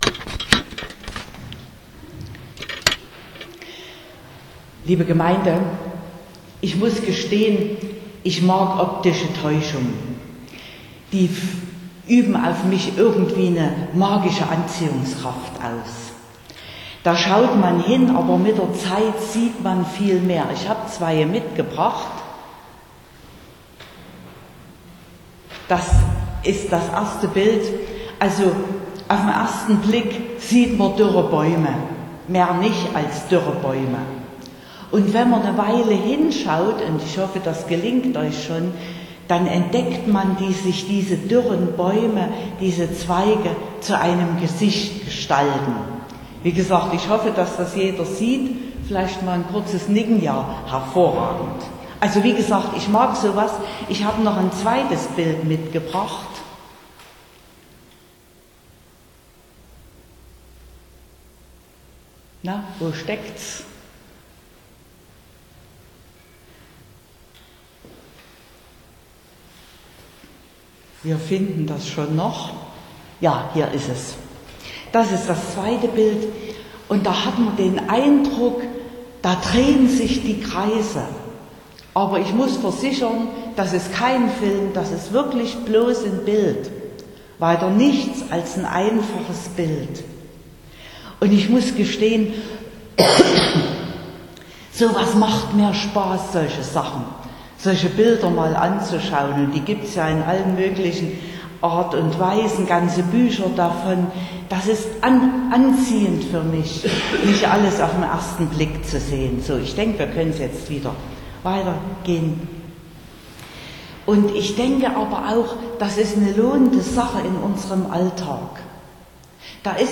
13.05.2021 – Gottesdienst
Predigt (Audio): 2021-05-13_Offene_Augen_des_Herzens.mp3 (21,7 MB)